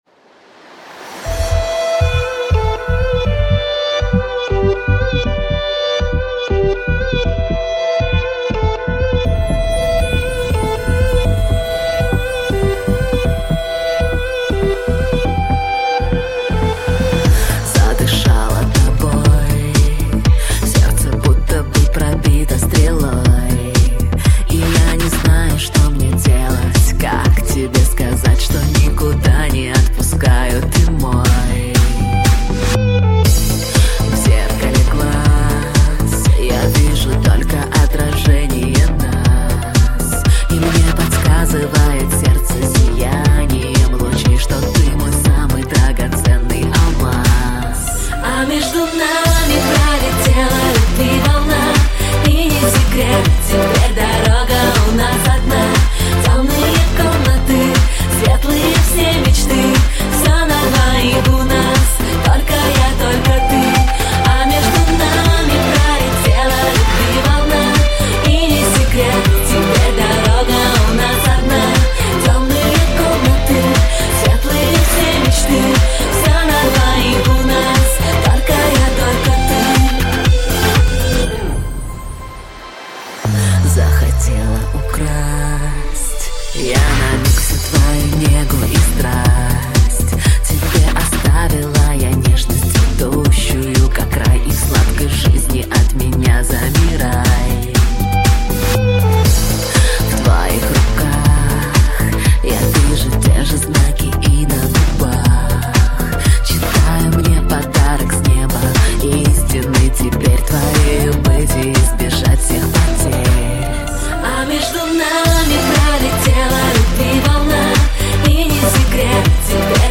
Категория: Русский Рэп/ Хип - Хоп